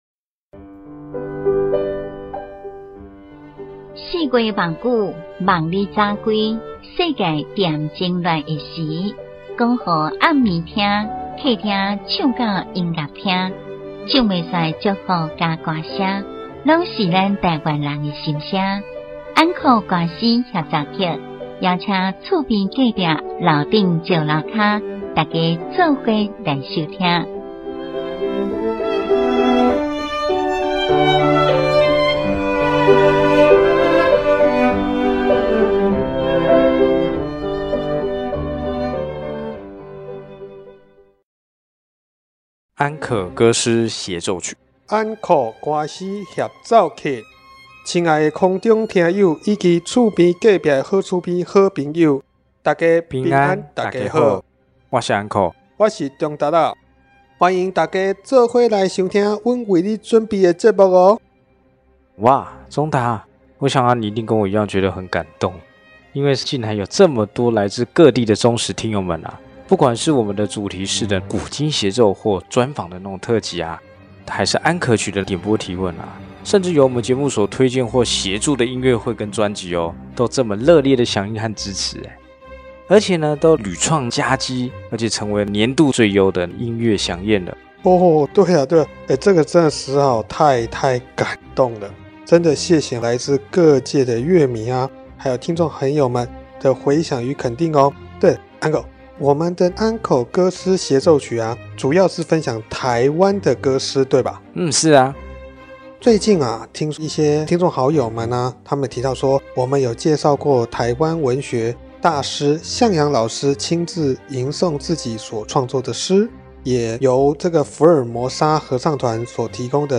鄒族傳統舞蹈-狩獵 、泰雅-口簧琴 、布農族-弓琴 、魯凱族-雙鼻笛、邵族合奏樂器-樂杵、阿美-腰鈴舞、Amis馬蘭部落複音歌樂
(加入排灣族傳統樂器鼻笛)